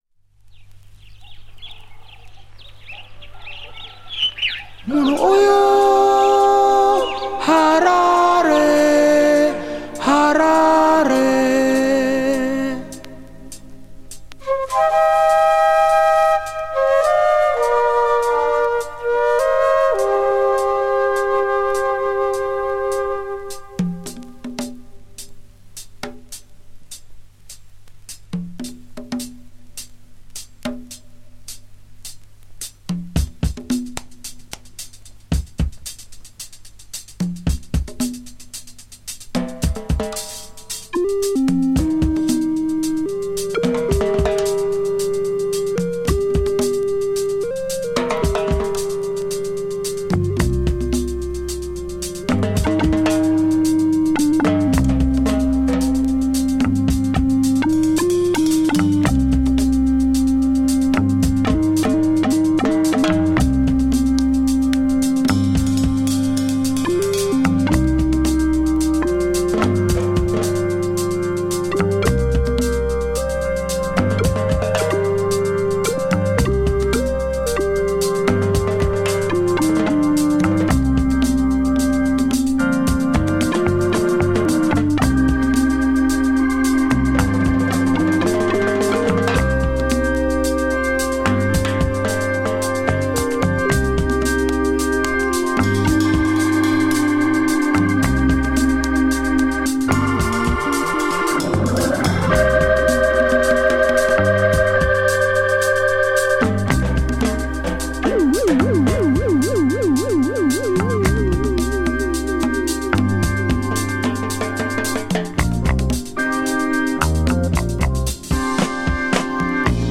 ディープでエモーショナルな